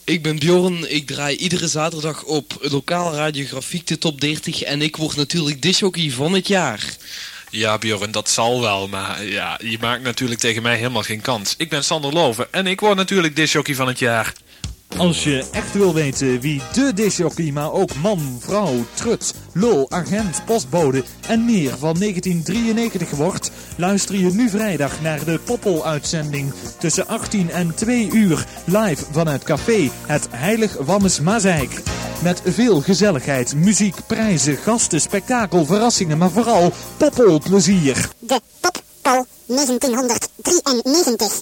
download Promotiejingle Pop-Poll-uitzending